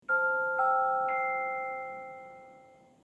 Звуки уведомлений Samsung